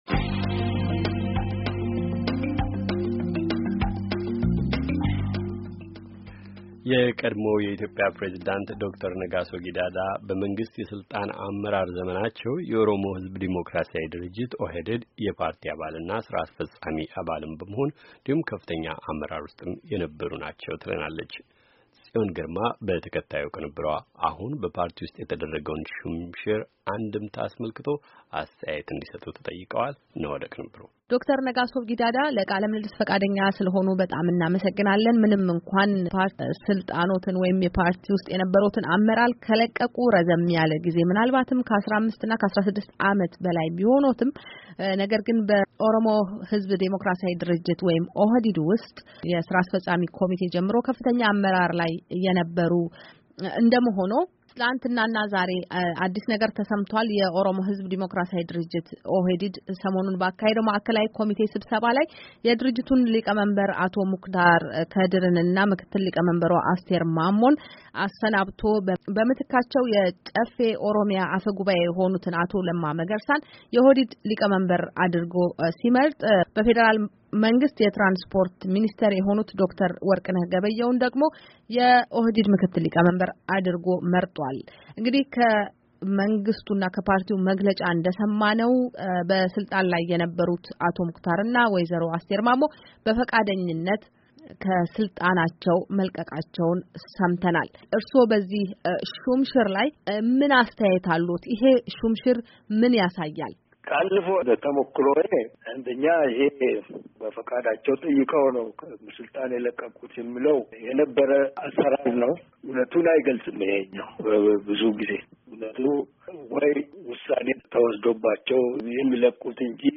ከኦህዴድ ሹም ሽር ጋር በተያያዘ ከዶ/ር ነጋሶ ጊዳዳ ጋር የተደረገ ቃለ ምልልስ